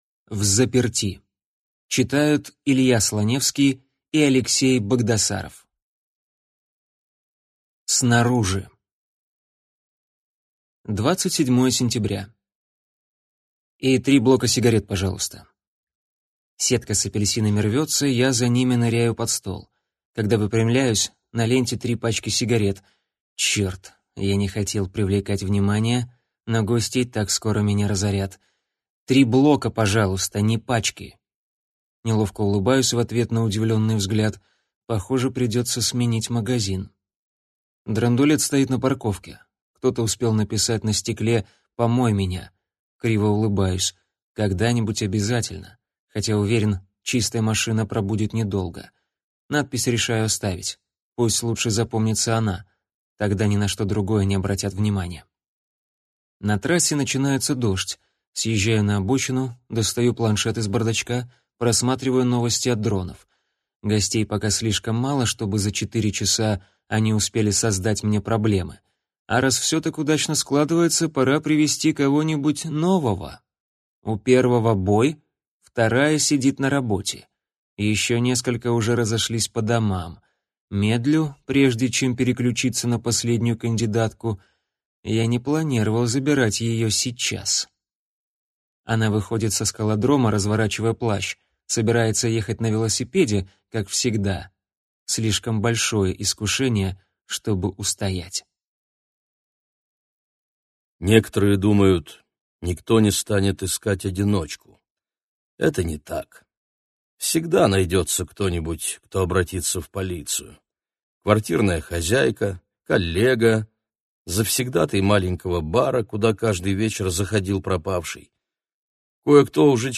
Аудиокнига Взаперти | Библиотека аудиокниг